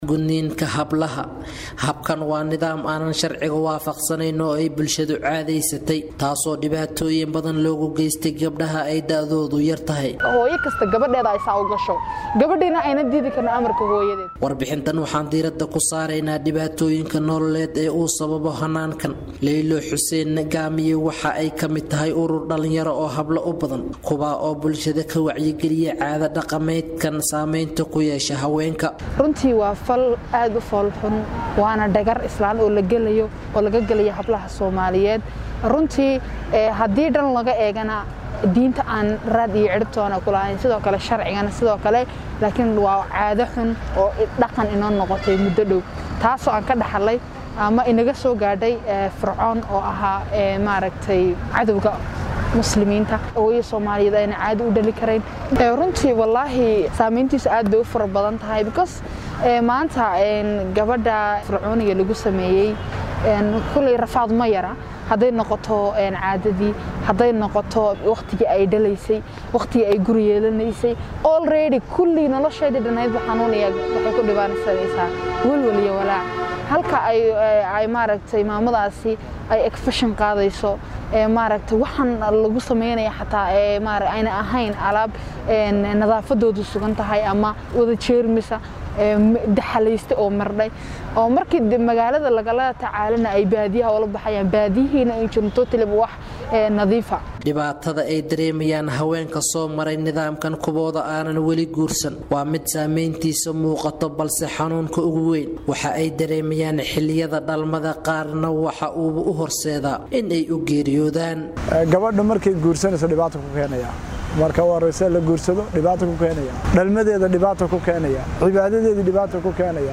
DHAGEYSO:Warbixin: Dhibaatada gudniinka fircooniga ee FGM-ka